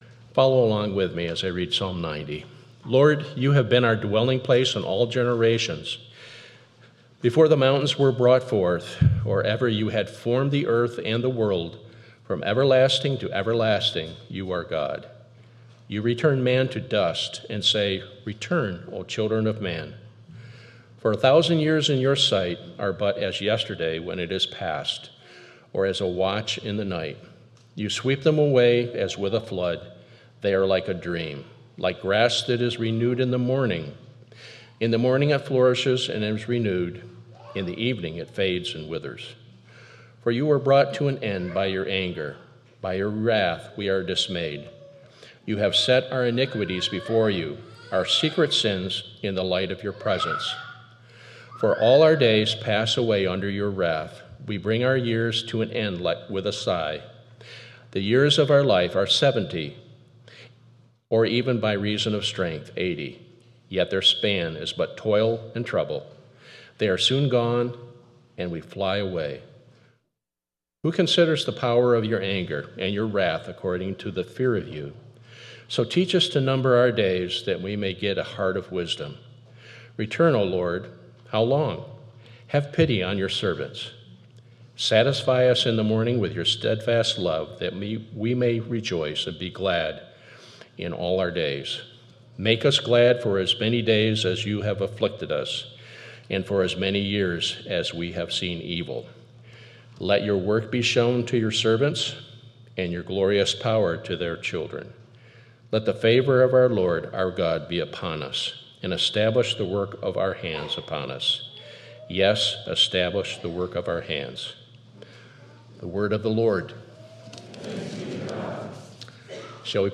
8.3.25 Sermon.m4a